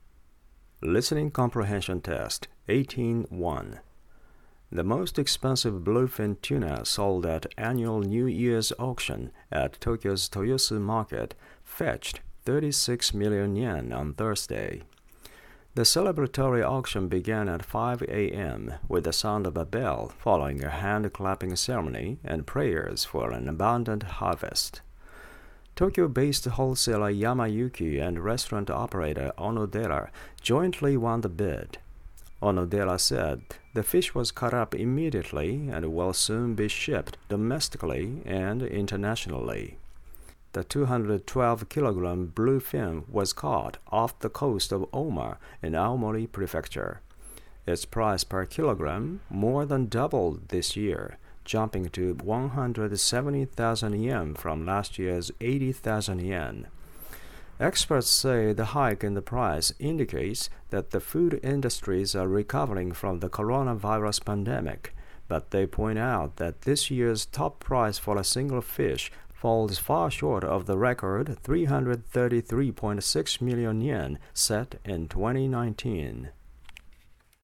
毎回こんなニュースを2本取り上げています（18-1）
これは著作権の関係で僕が読んでいますが